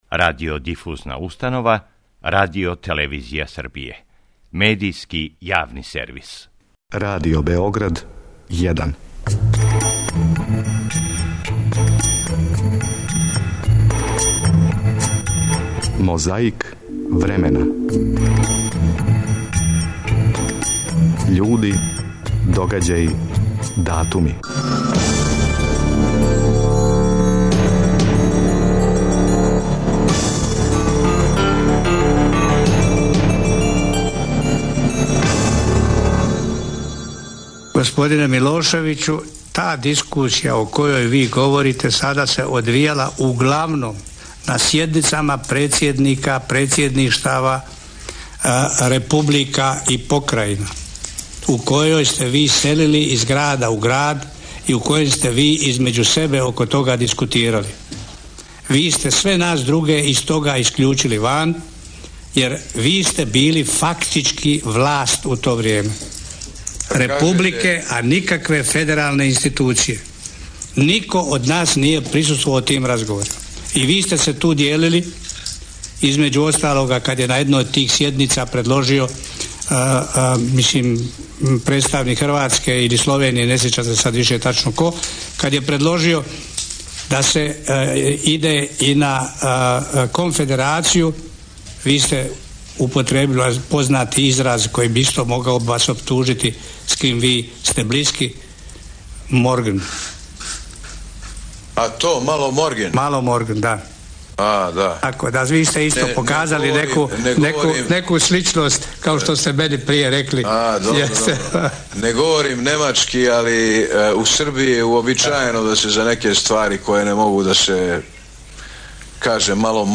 19. јануара 1993. године, на Палама код Сарајева, Скупштина Републике Српске расправљала је о Венс-Овеновом плану. Говорио је и председник Републике Српске Радован Караџић.